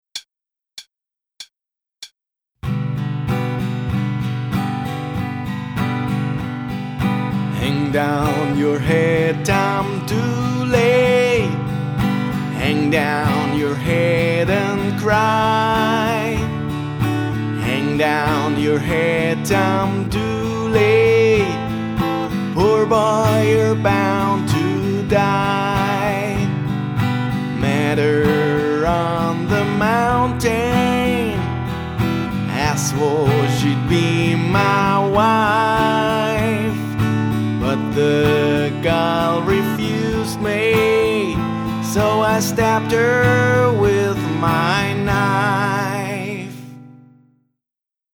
Die 24 Songs wurden alle vom Autor nicht nur mit der Gitarre extra langsam eingespielt, sondern auch eingesungen.